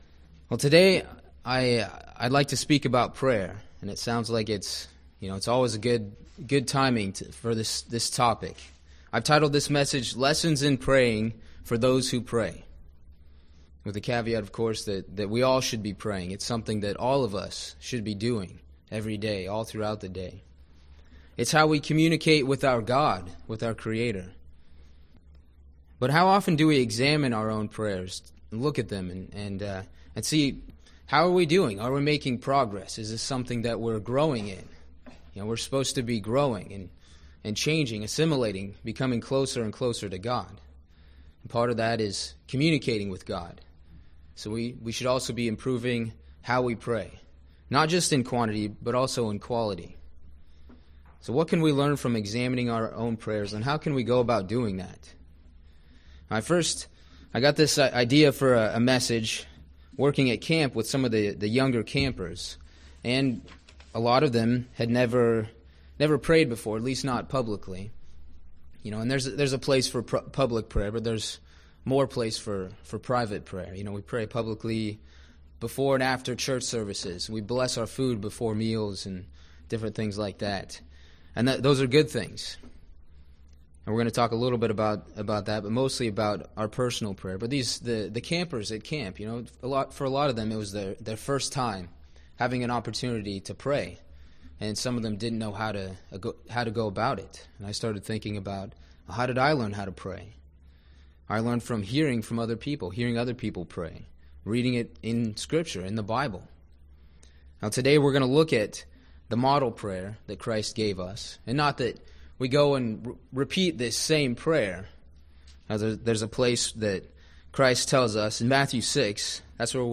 This sermon takes a look at the elements of the model prayer in Matthew chapter 6 while discussing how we can improve our communication with Heavenly Father.